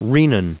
Prononciation du mot renin en anglais (fichier audio)
Prononciation du mot : renin